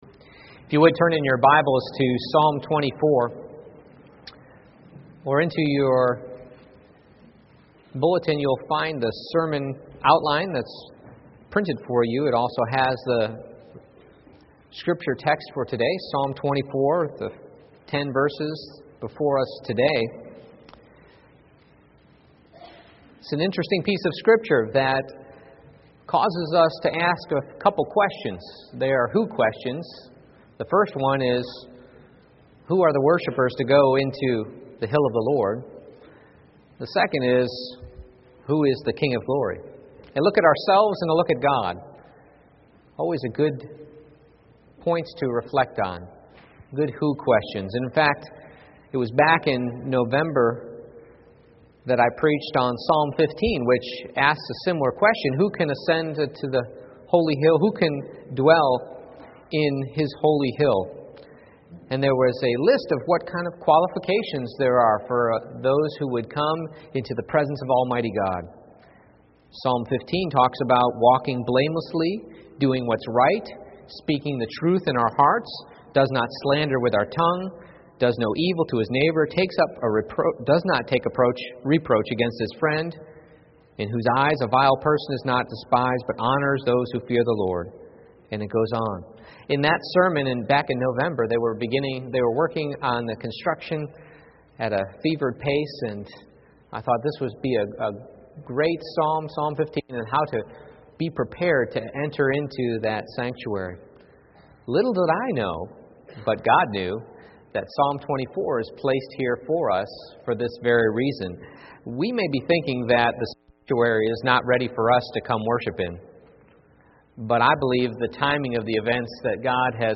Psalm 24:1-10 Service Type: Morning Worship I. It's ALL GOD's II.